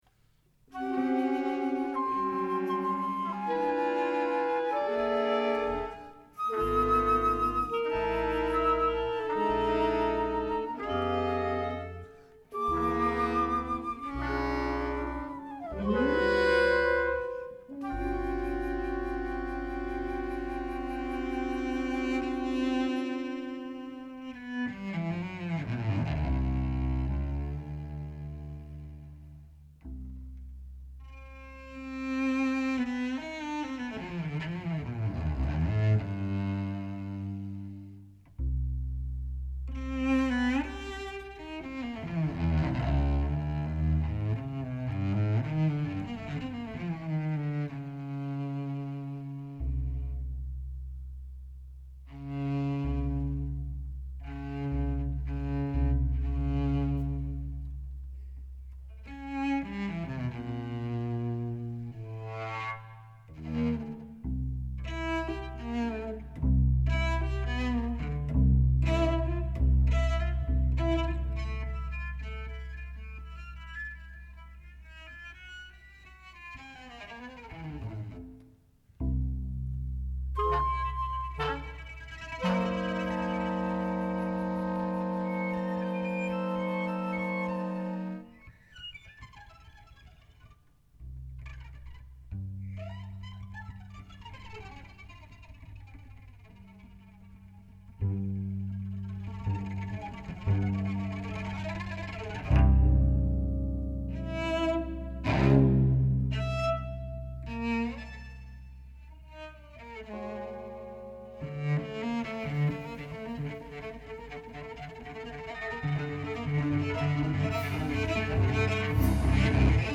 tenor sax, clarinet
alto sax, soprano sax, flutes
bass clarinet, clarinet
electric cello
trumpet
trombone
piano/keyboard/sampler
guitar
electric bass
drums